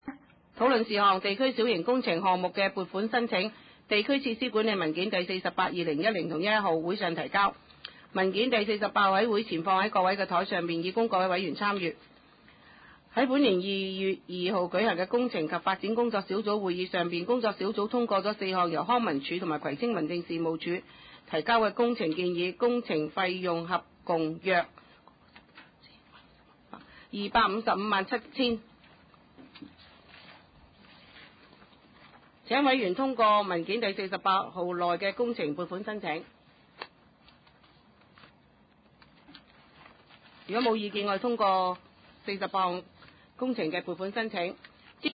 第六次會議(一零/一一)
葵青民政事務處會議室